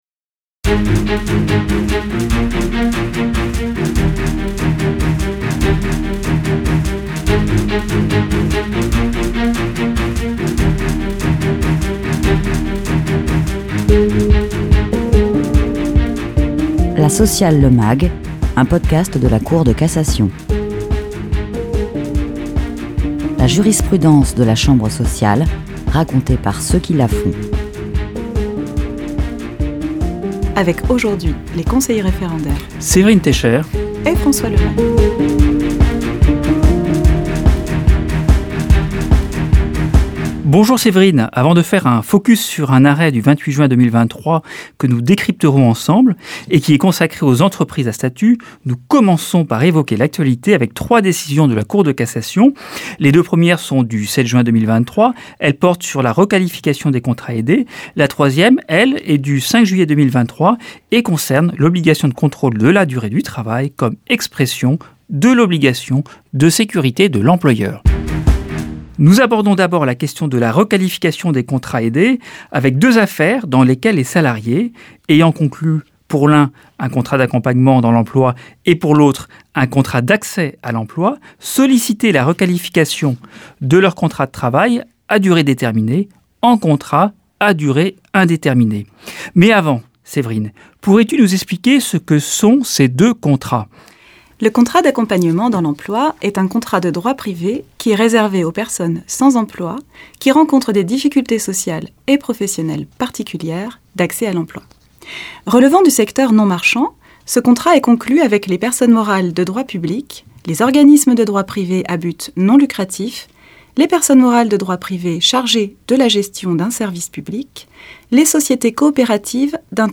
Reportage du 10 décembre 2017 au coeur du Palais Chaillot.